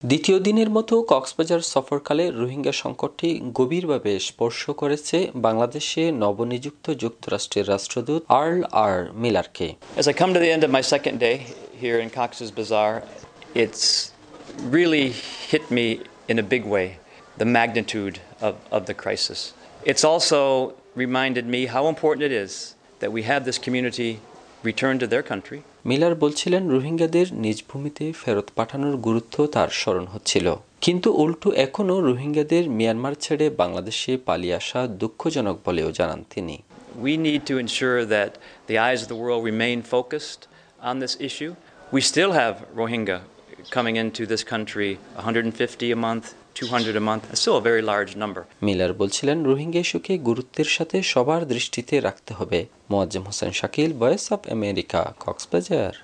কক্সবাজার থেকে
রিপোর্ট।